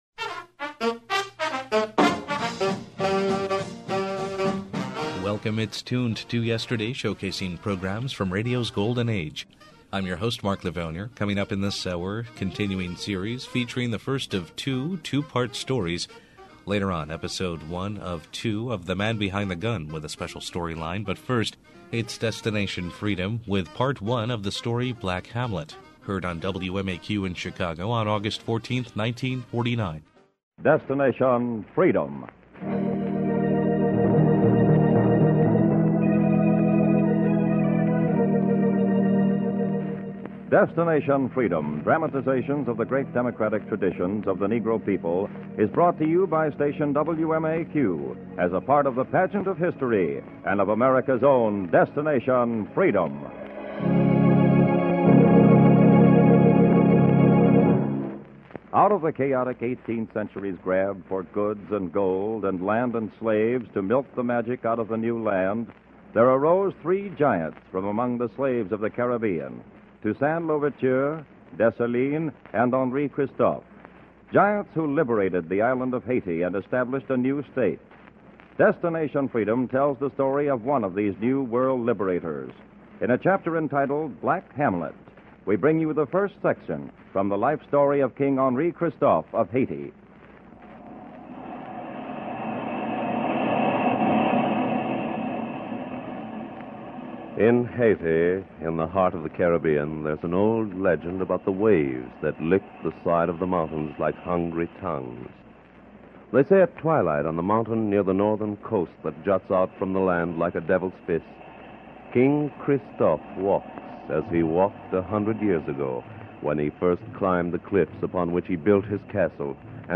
Classic Radio
Golden Era Audio Drama